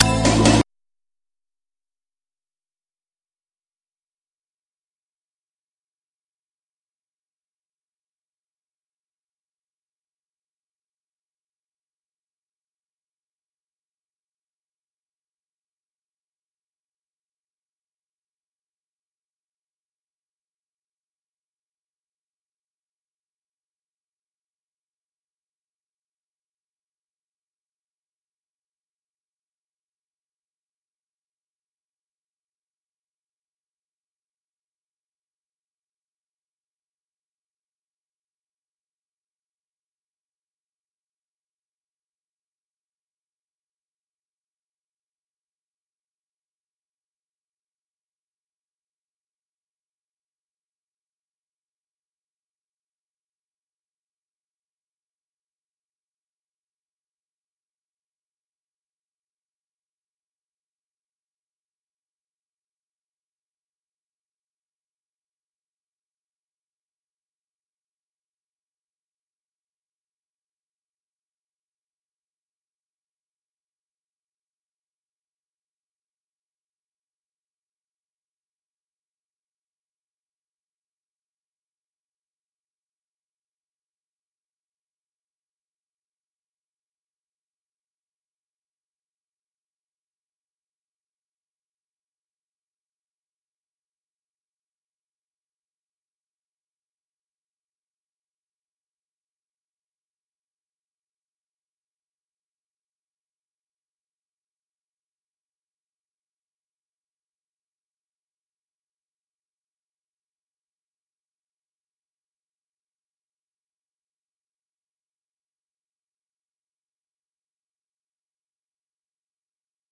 ເພງລາວສະໄໝ ຂອງສິນລະປິນລາວ ໃນຕ່າງແດນ